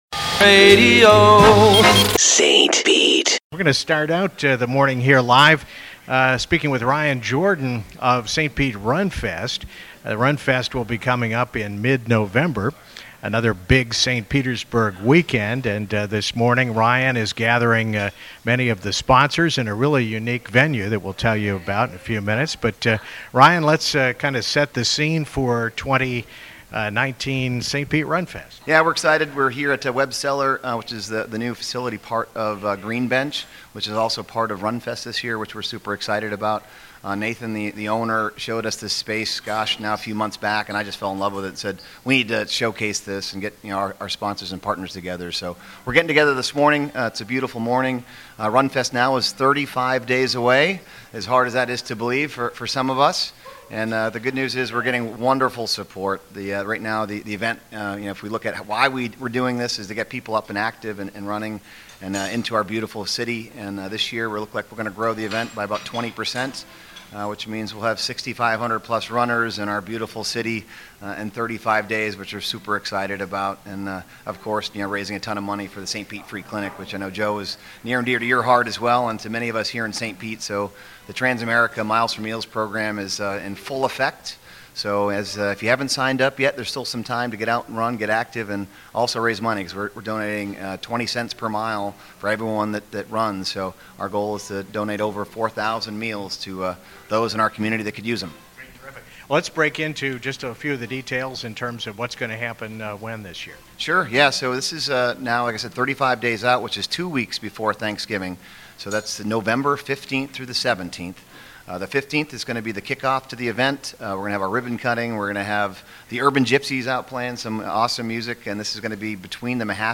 20-19 St. Pete Runfest Sponsor Breakfast at Webb City Cellar 10-10-19 Live Event Coverage